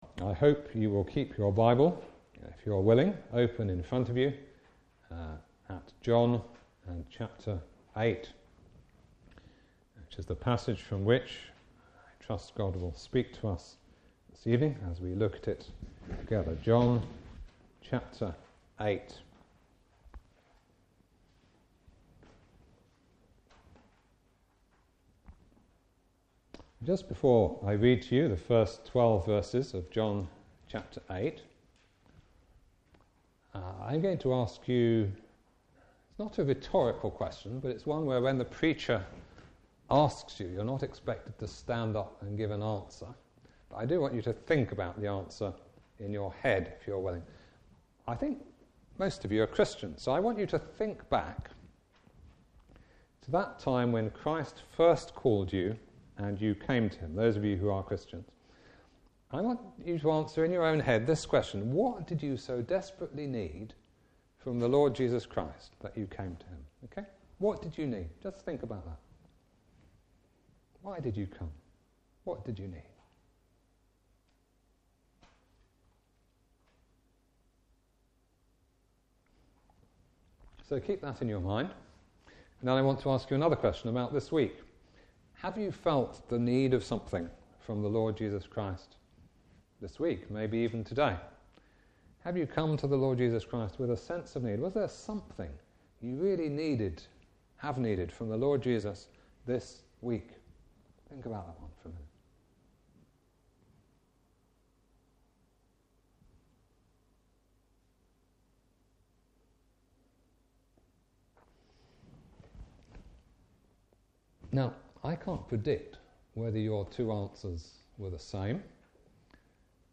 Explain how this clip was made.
Service Type: Evening Service Bible Text: John 8:1-11.